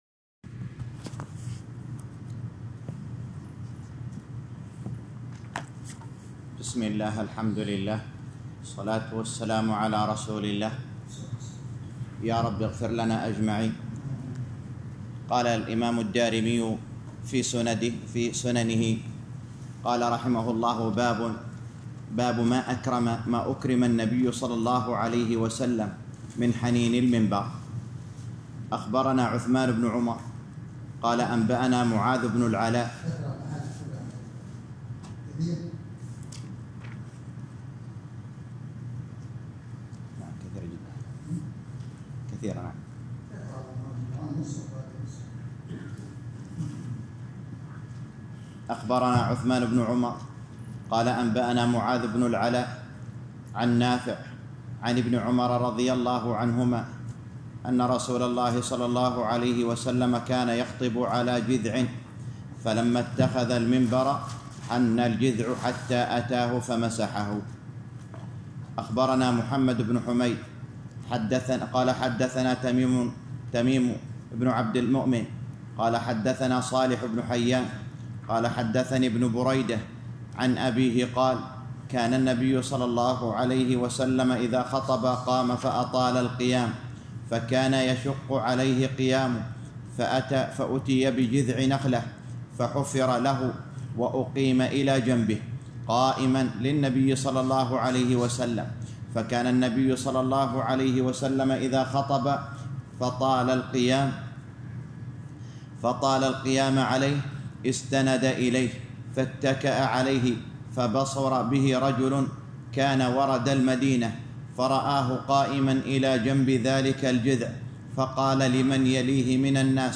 الدرس الثاني عشر - شرح سنن الدارمي الباب السادس _ 12